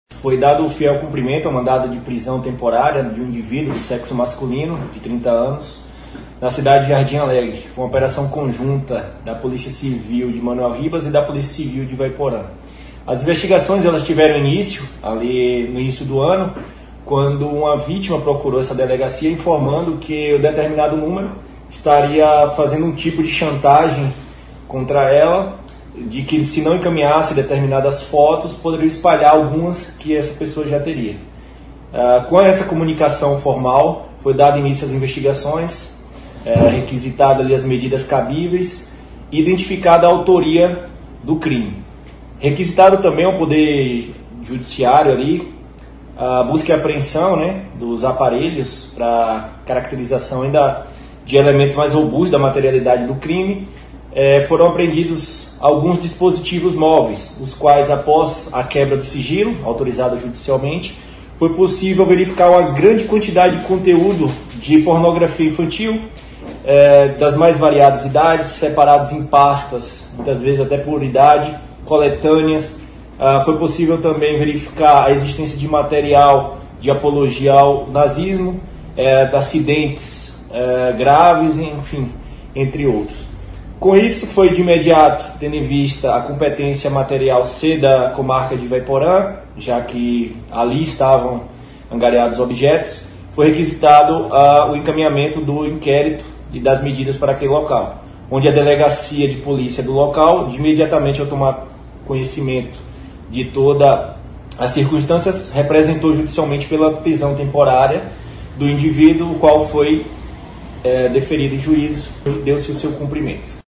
A investigação começou quando uma vítima denunciou estar sendo chantageada pelo suspeito. Ouça o que diz o delegado